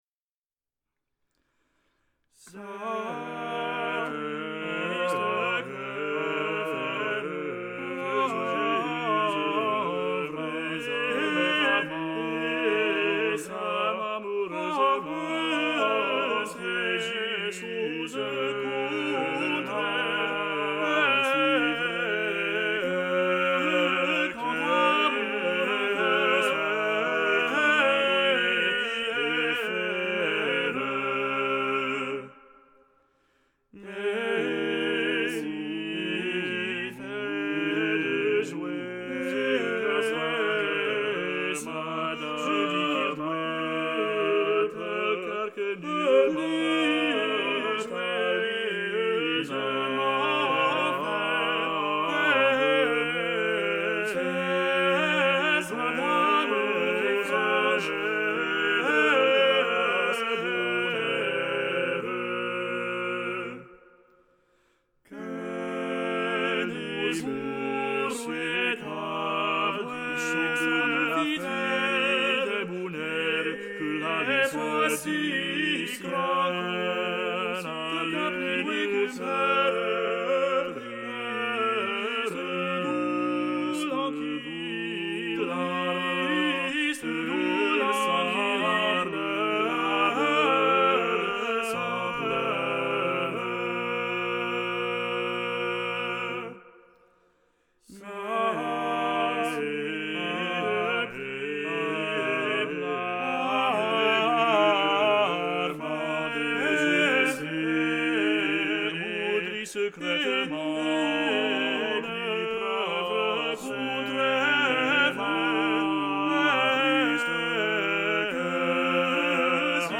Previously, the online subscription version (was available via CUP) and was accompanied by sound files that enable the listener to hear the individual voice parts, any two in combination, and the complete three-part balade.
1-cantus_contratenor_tenor.mp3